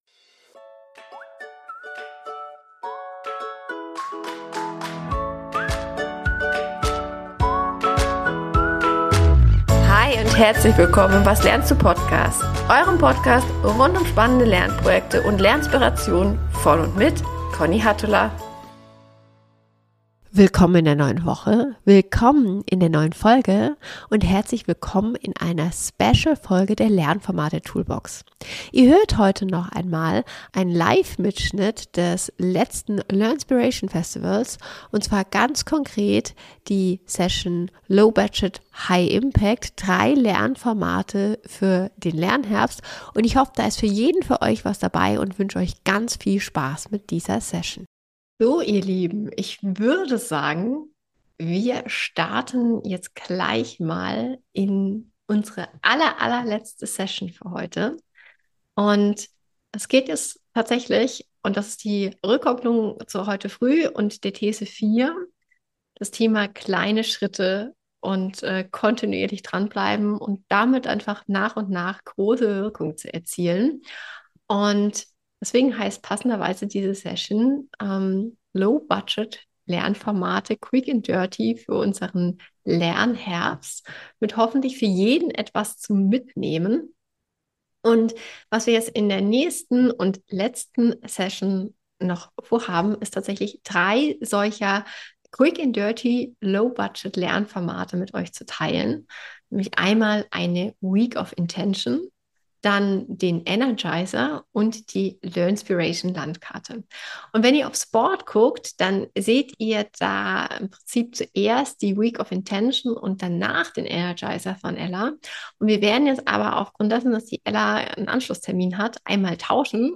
Diese Woche gibt es als Mitschnitt vom Learnspiration Festival drei Low Budget/High Impact Lernformate für euren Lernherbst.